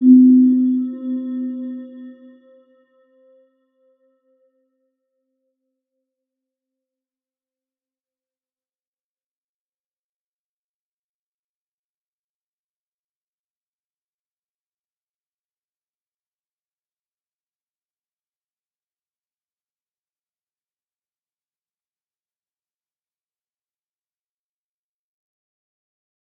Round-Bell-C4-p.wav